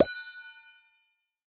Minecraft Version Minecraft Version 25w18a Latest Release | Latest Snapshot 25w18a / assets / minecraft / sounds / block / trial_spawner / eject_item1.ogg Compare With Compare With Latest Release | Latest Snapshot
eject_item1.ogg